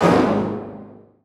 HIT 6 .wav